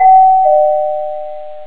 doorb2.wav